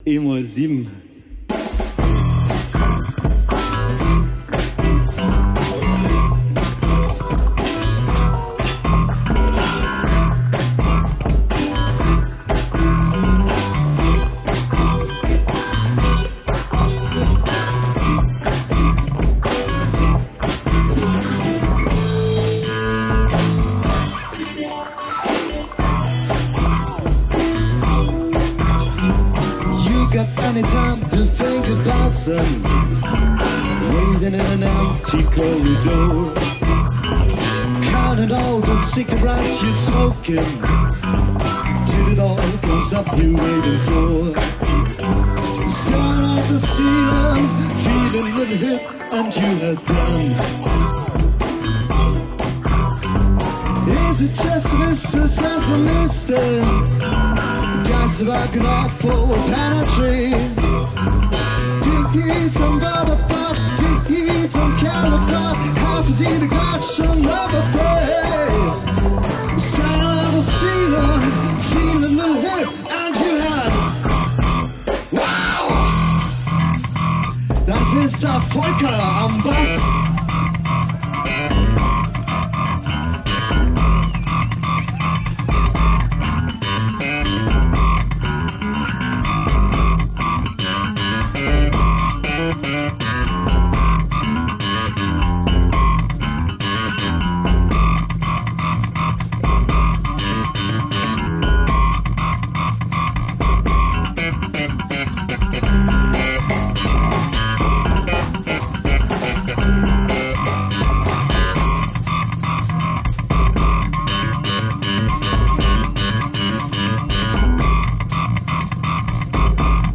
funk rock band
Vocals
Drums
Guitar
Bass
Keyboard